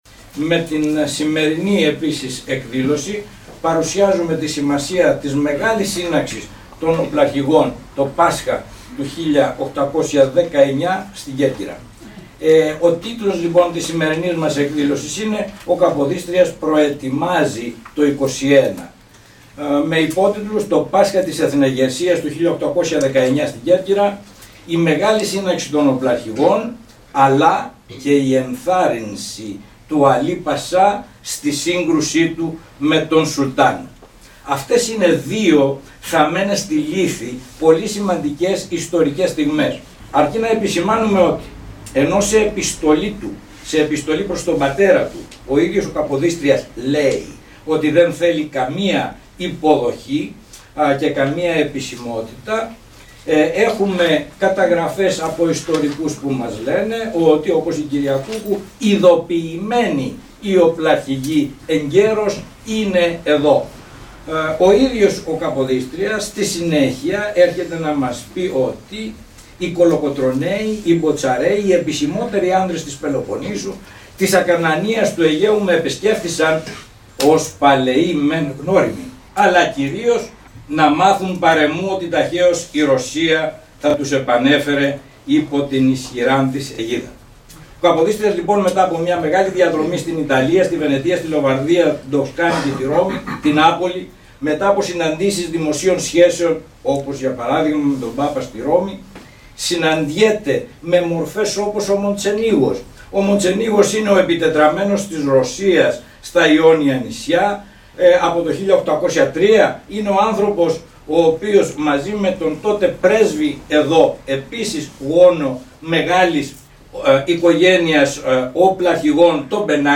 απόσπασμα της ομιλίας του οποίου ακούμε.